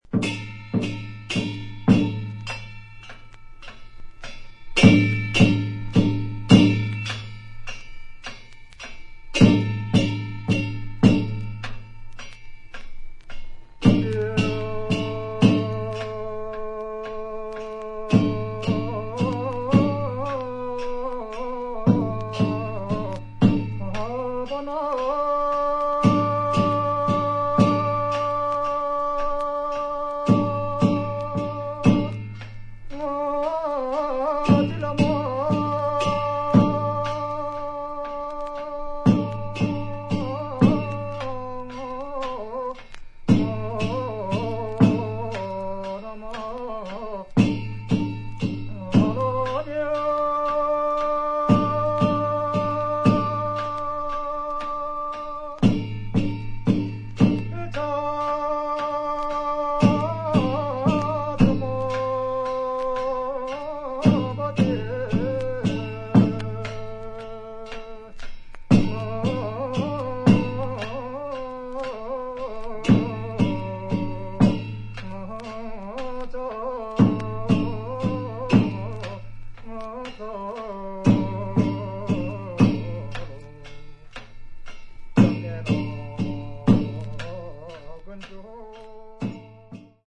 Folk-song